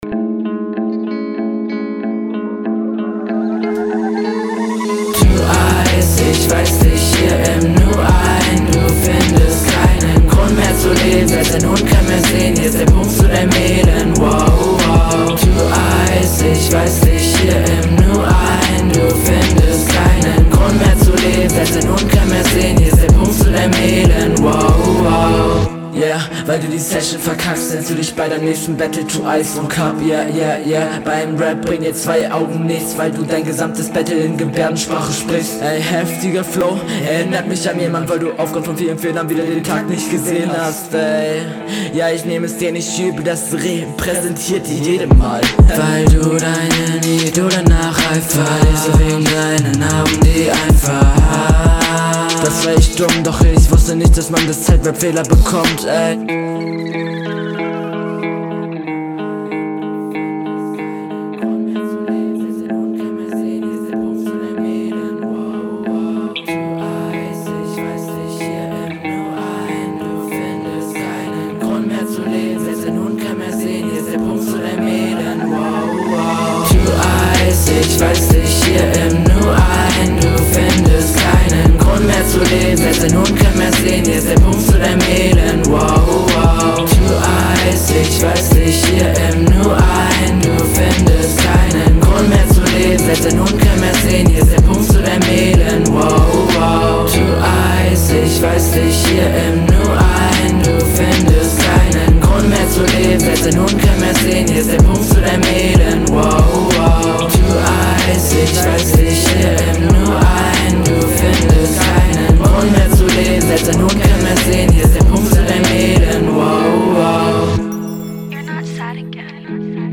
Wieder wunderschöner Beat. Hook direkt wieder ein Ohrwurm und diesmal auch nicht etwas schief gesungen …
Soundqualität: Unglaublicher Hörgenuss, sehr schöne Hook.